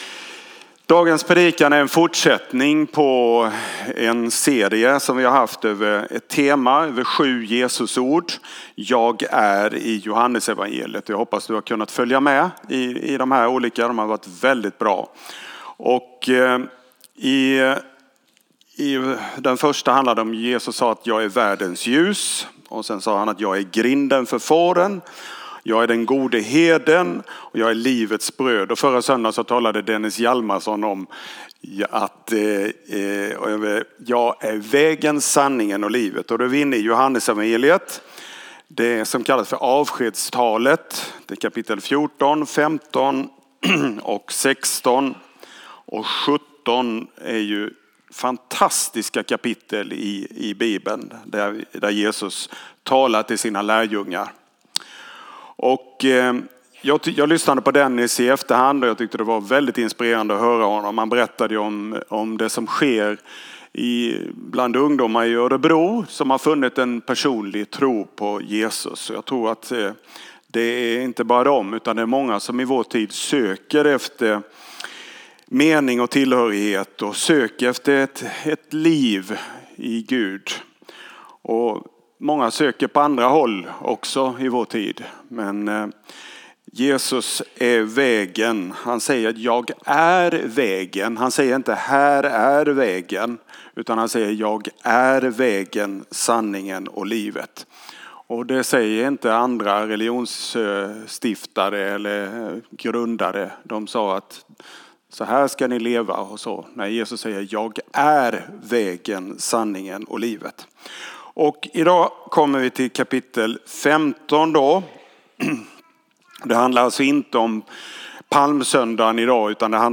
A predikan from the tema "7x Jag är."